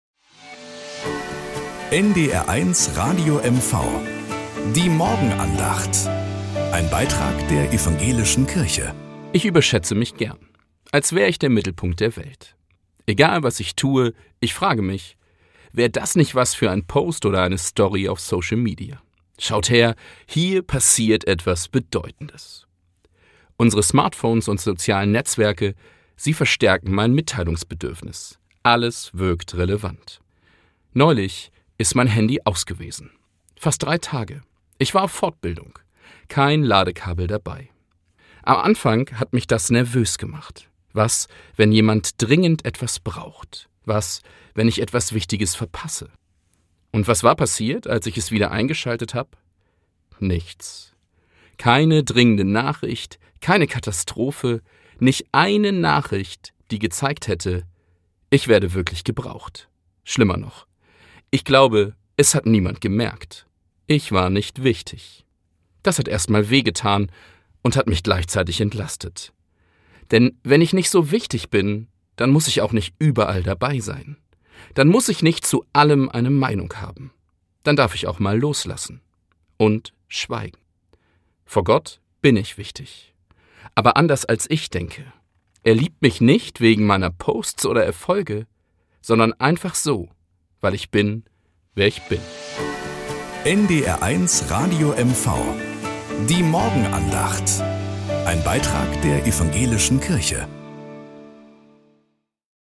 Morgenandacht. Evangelische und katholische Kirche wechseln sich